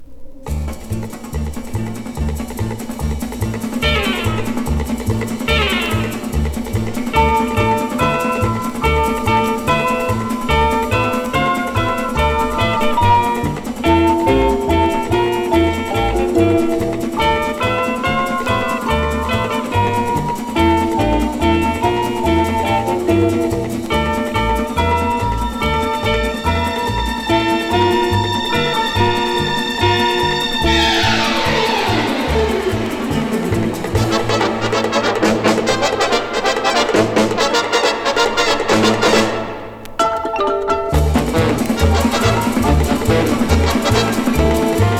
World, Easy Listening, Pops　USA　12inchレコード　33rpm　Mono